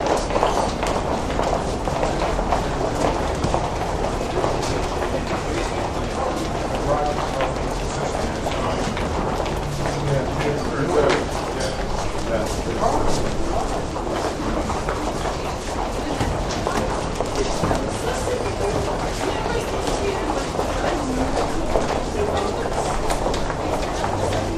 Corridor Footsteps With Light Walla